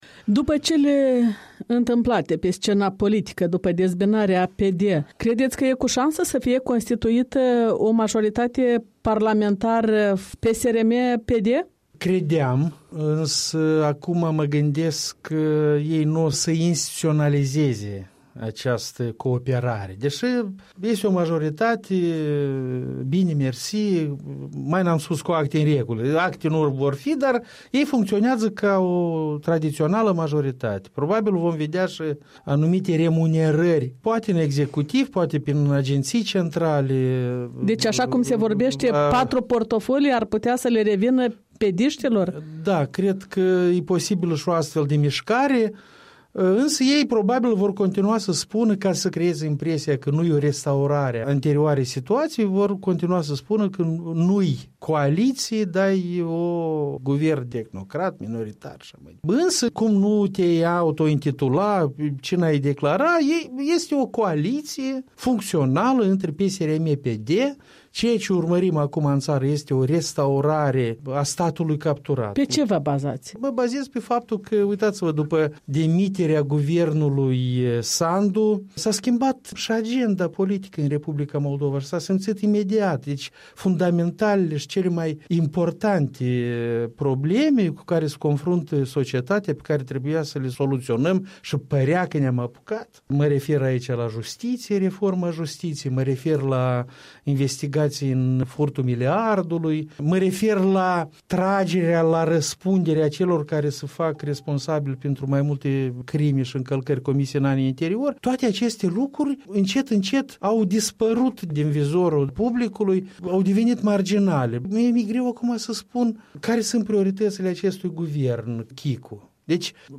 O discuție cu un analist despre cele 100 de zile ale guvernului Chicu.
Interviu